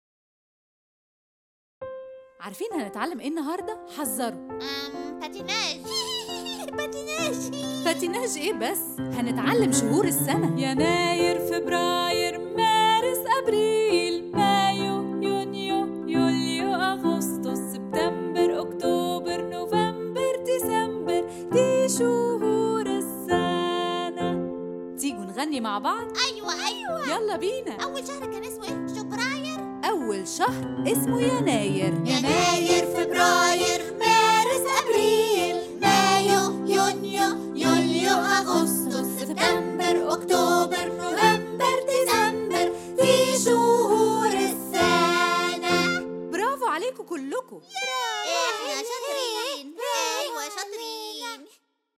Their catchy rhythm and use of repetition gently boost their memory and vocabulary.